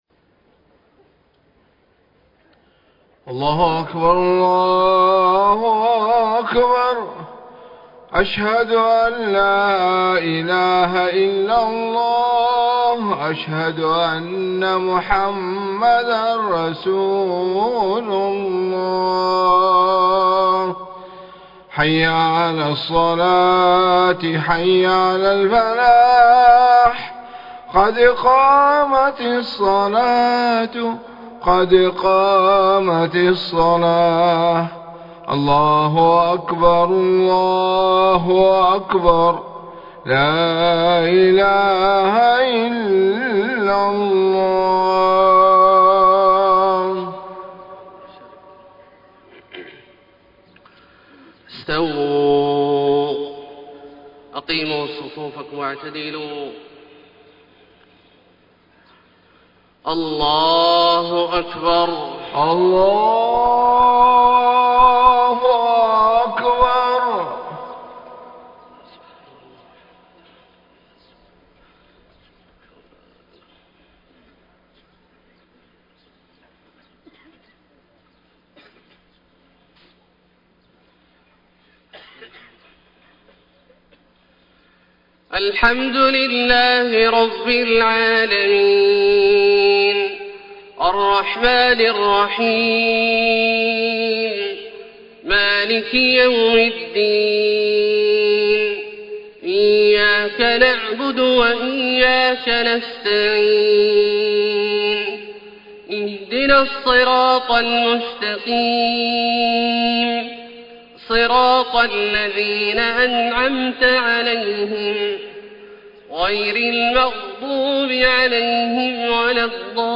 صلاة الفجر 1-1-1433 من سورة الصف > 1433 🕋 > الفروض - تلاوات الحرمين